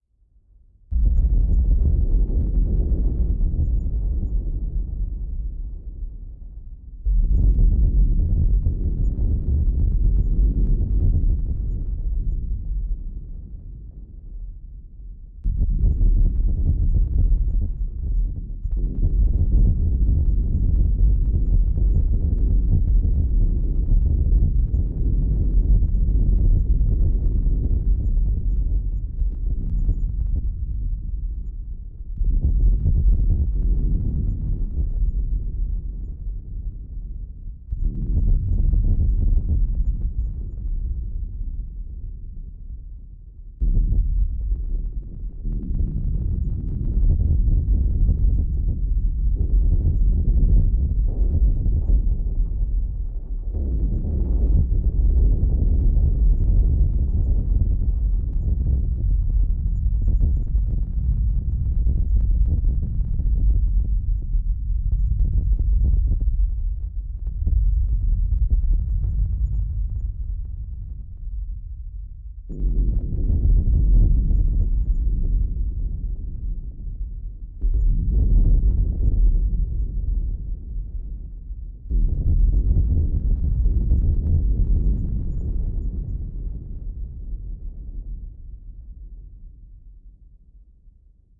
描述：雷声中有很多低端谐波。它实际上是Access Virus B合成器的非常稳定的振荡器+噪声源。
Tag: 闪电 天气 滚滚 雷声 雷暴 雷雨 雷声 性质 现场记录ING